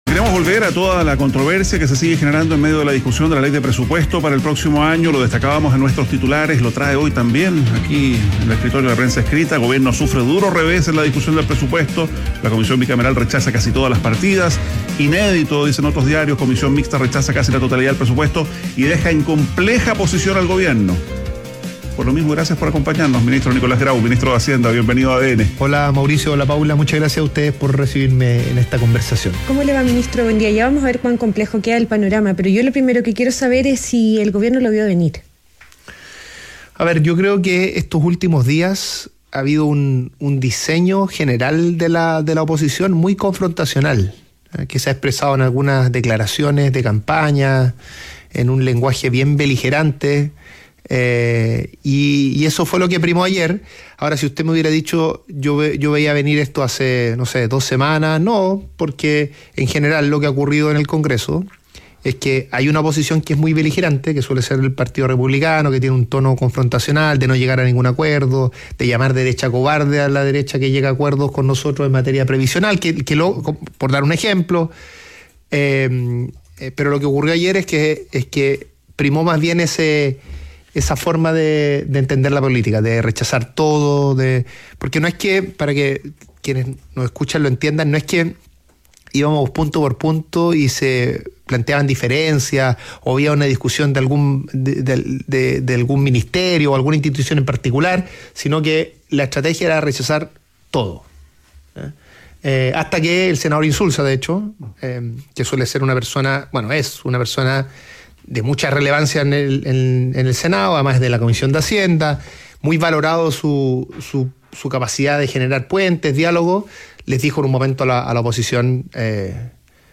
Entrevista a Nicolás Grau, ministro de Hacienda - ADN Hoy